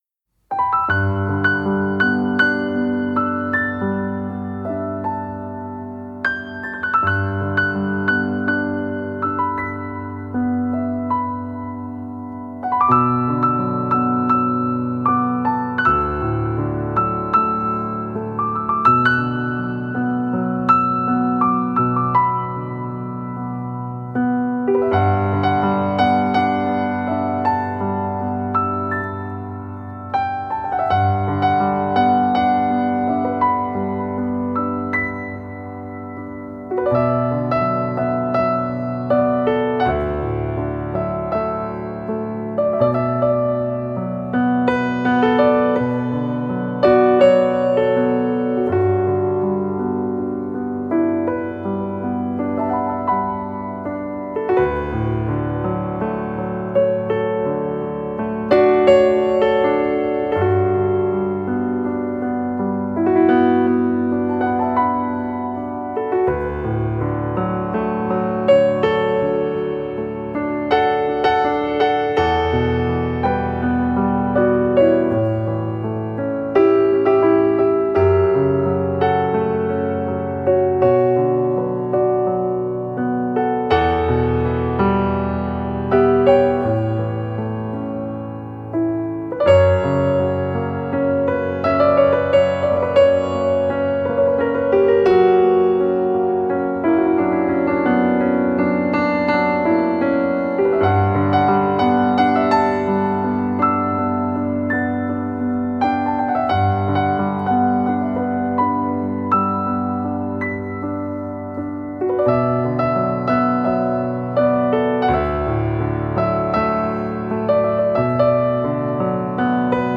موسیقی بی کلام آهنگ بی کلام